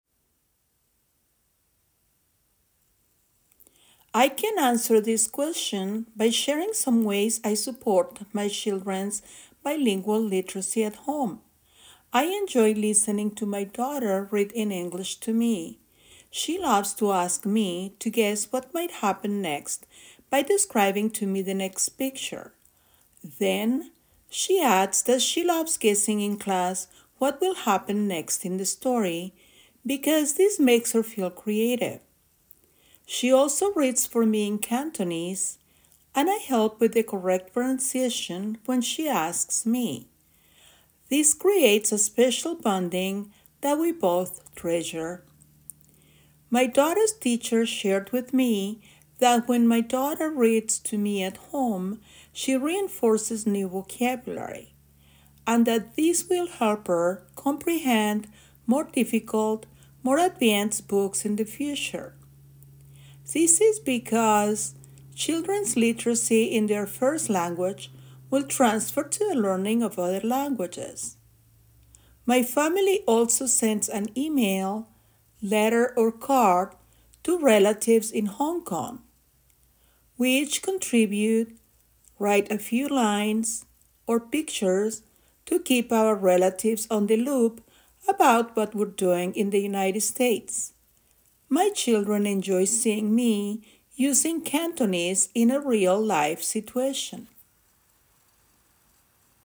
[Note: In the transcript below, ellipses indicate that the speaker paused.]
The response effectively communicates clear and logically sequenced ideas delivered with a consistent flow of speech, few pauses, intelligible pronunciation, and appropriate intonation.
Examples of such errors include some inconsistency in the flow of speech and few pauses.